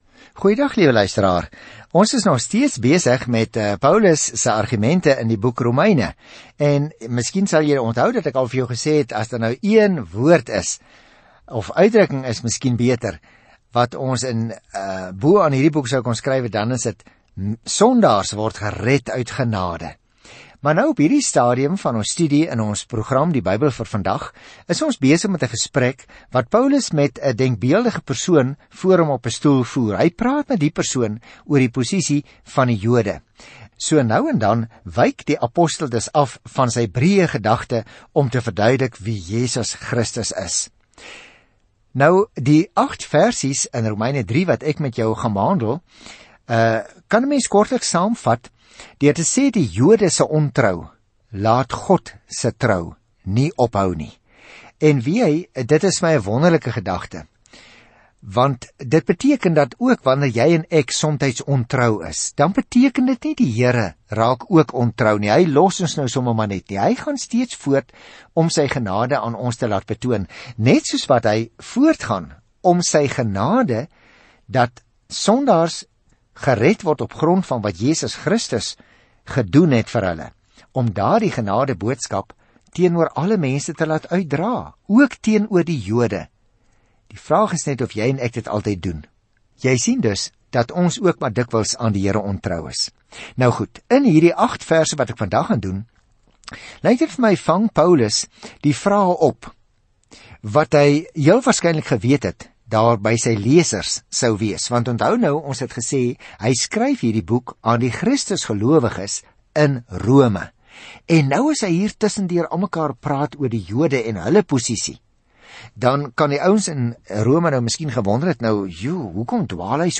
Reis daagliks deur Romeine terwyl jy na die oudiostudie luister en uitgesoekte verse uit God se woord lees.